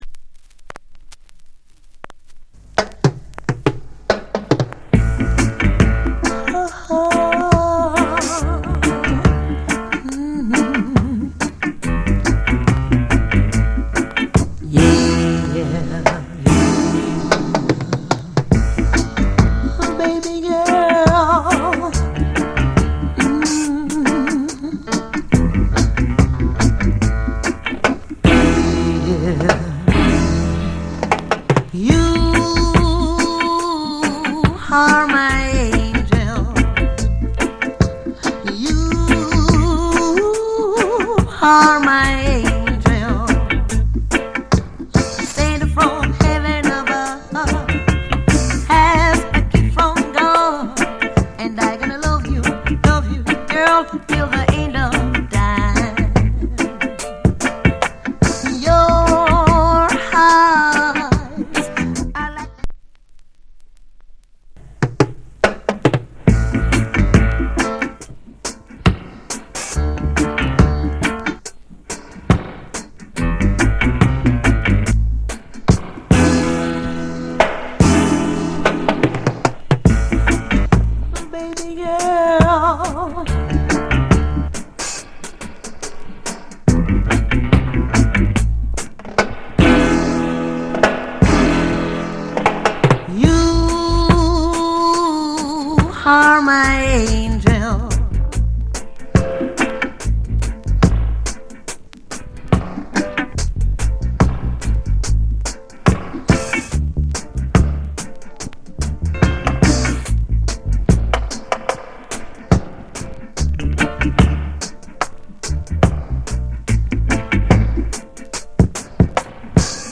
** Deep & Hard Vo & Dub Wise.